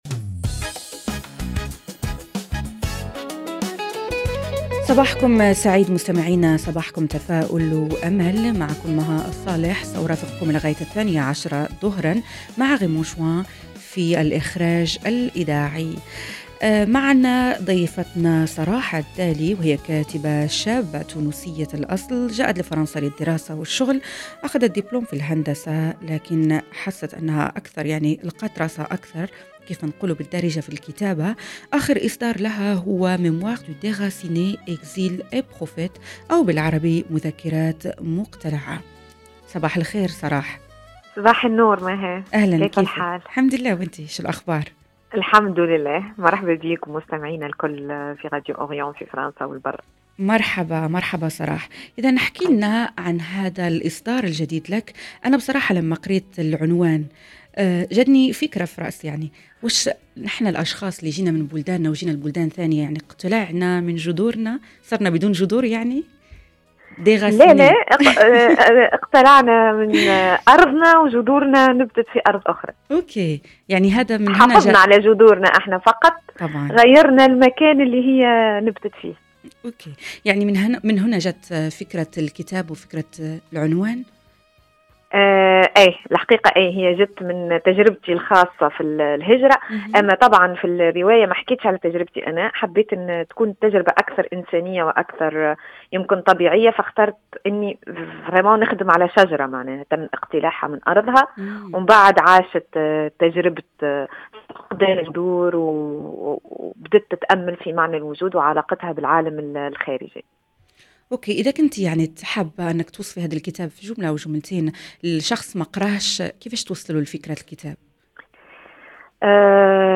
وخلال الحوار نقترب من الكاتبة لنكتشف خلفيات هذه الرواية وكيف يمكن للكلمات أن تصبح وطناً بديلاً حين تضيع الجذور.